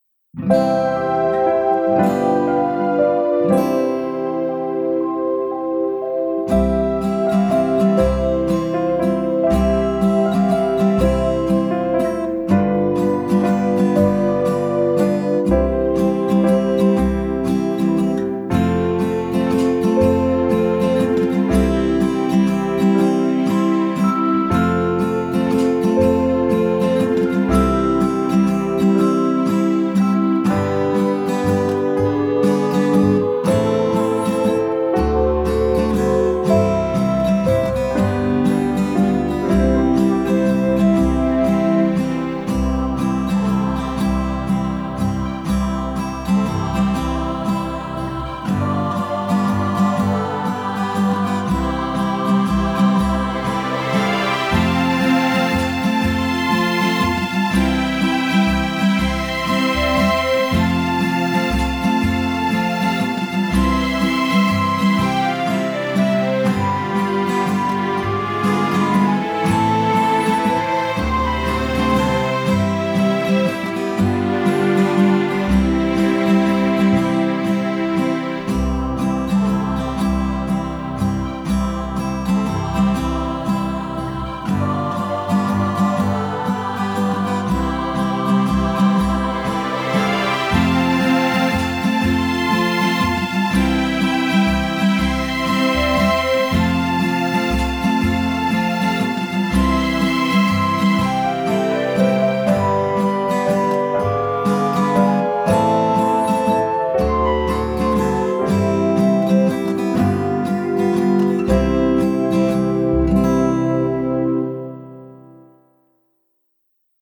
Genre: Christmas.
Silent-Night-Custom-Backing-Track.mp3